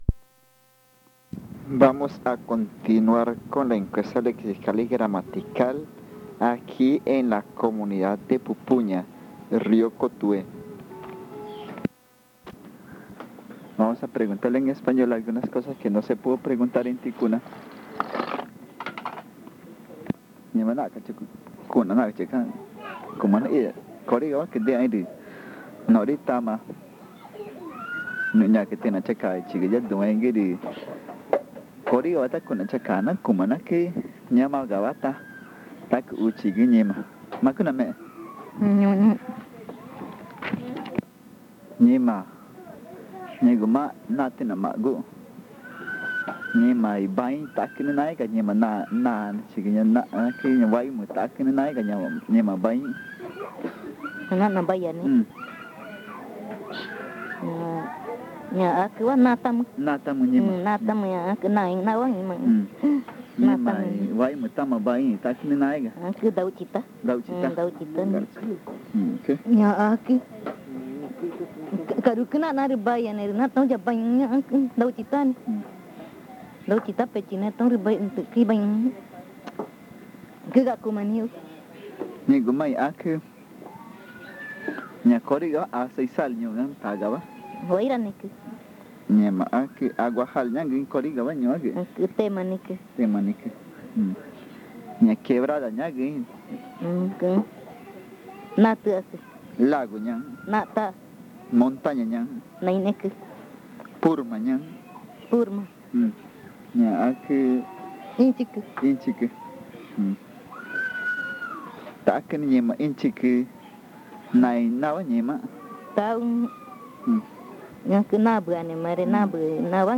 Encuesta léxica y gramatical 14. Pupuña n°3